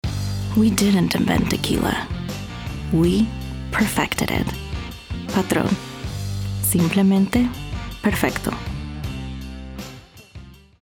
Tequila, Sophisticated, Sultry - spanish tagline